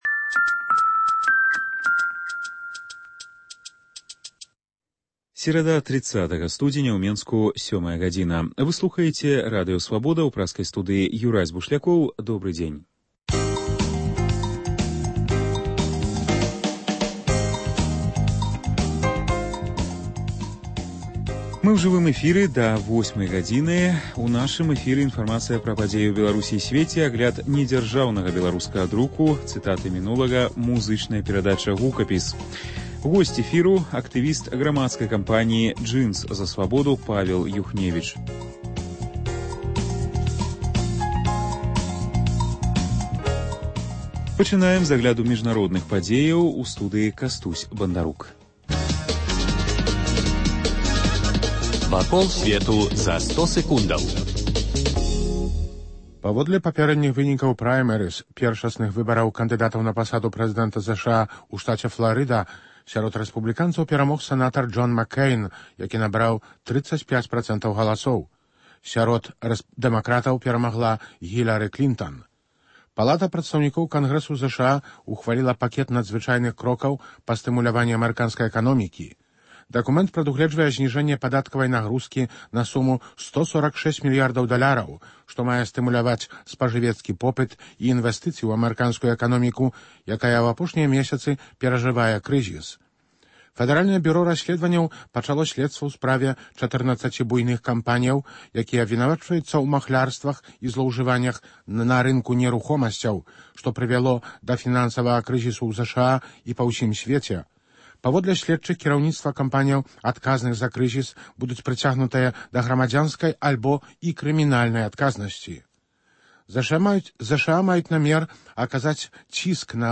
Ранішні жывы эфір
* Рэпартаж з устаноўчага сходу новай грамадзкай арганізацыі Інстытут нацыянальнай памяці.